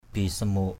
/bi-sa-mo:ʔ/ (t.) không thể với tới, bất khả thi. impossible. gruk bisamok g~K b{x_mK việc khó thực hiện.